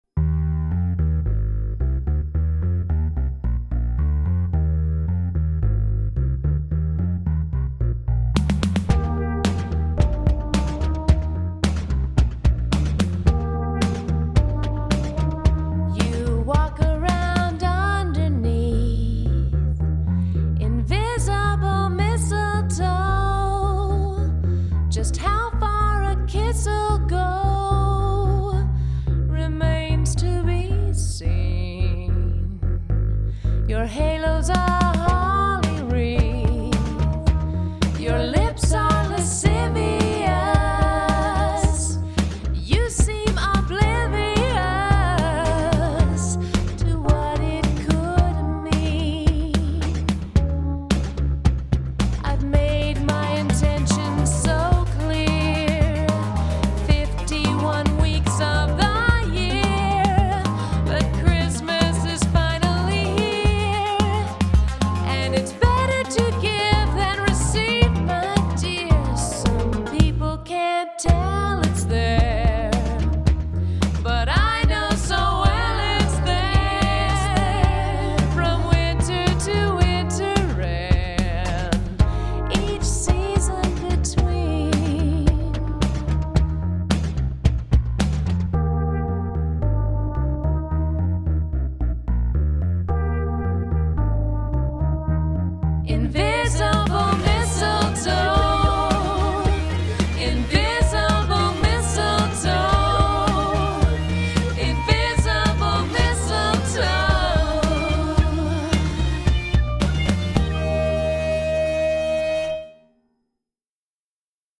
lo-fi Swedish reggae feel